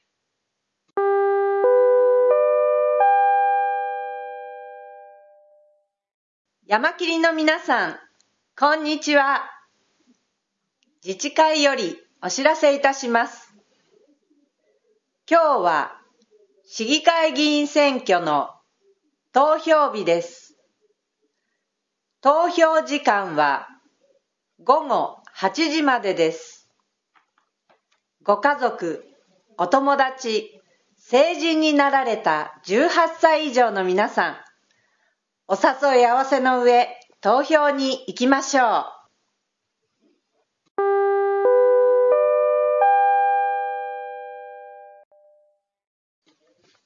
今回は、女性アナウンスに加え、男性アナウンスもあり何時もより変化に富んでいる。また、18歳以上の低年齢有権者への呼び掛けも行っている。